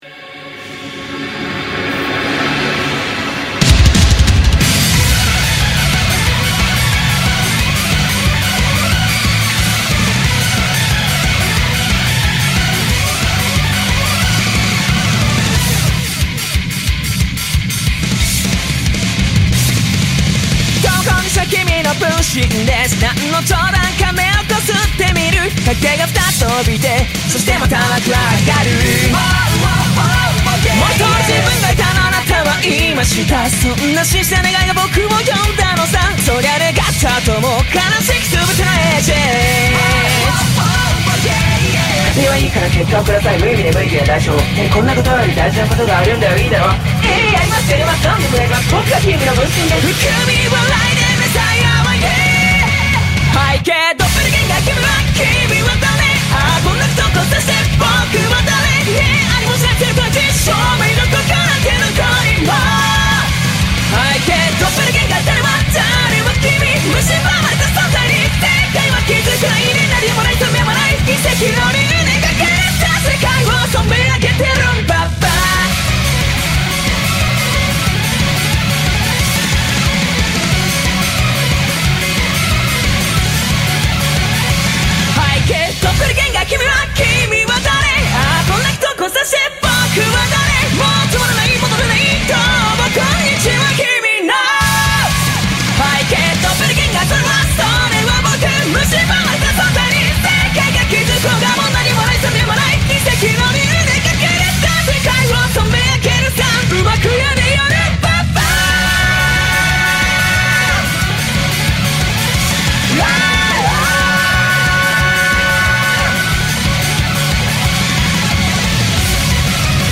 BPM181
Audio QualityPerfect (Low Quality)